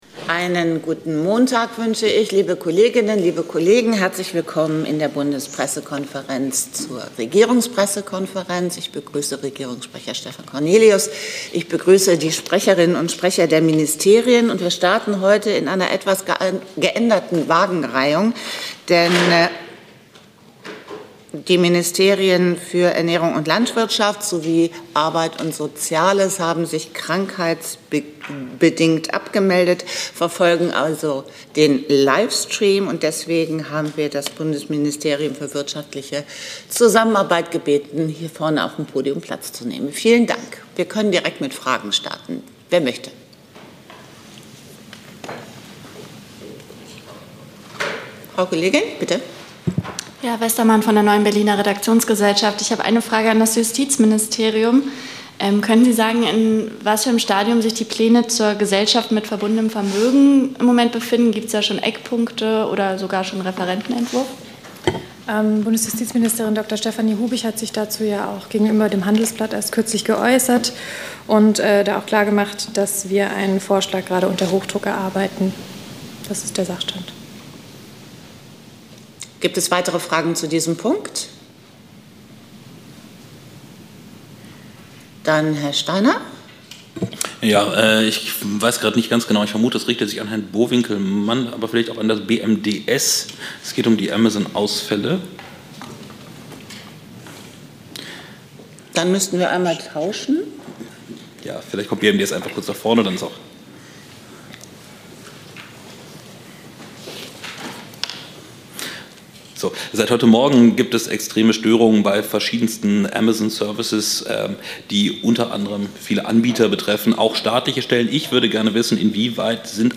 Regierungspressekonferenz in der BPK vom 20. Oktober 2025